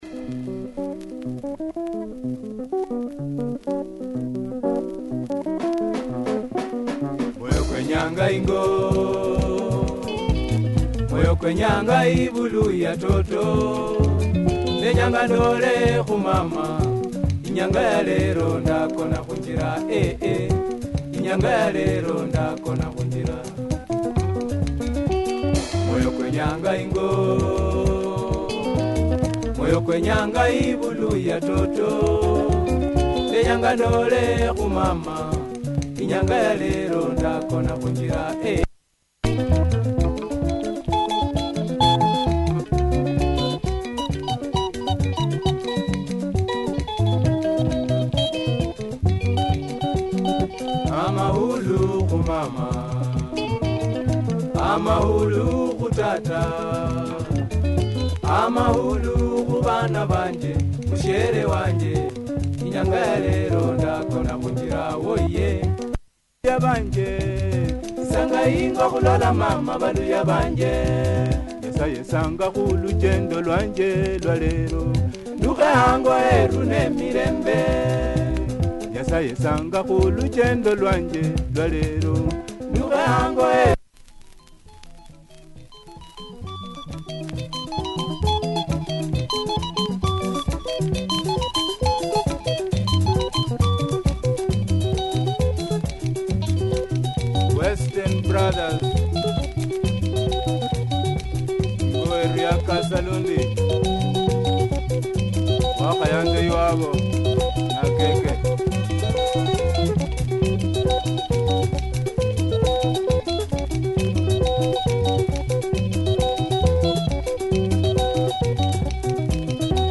Luhya Benga